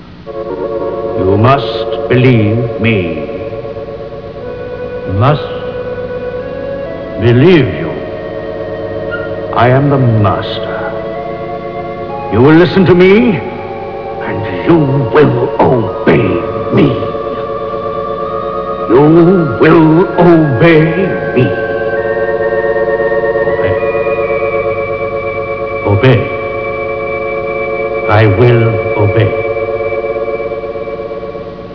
uses his hypnotic powers on yet another victim.